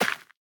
Minecraft Version Minecraft Version latest Latest Release | Latest Snapshot latest / assets / minecraft / sounds / block / rooted_dirt / break3.ogg Compare With Compare With Latest Release | Latest Snapshot